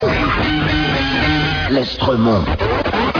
Avec d'autres voix